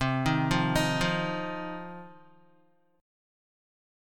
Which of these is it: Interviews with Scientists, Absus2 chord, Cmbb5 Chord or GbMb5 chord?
Cmbb5 Chord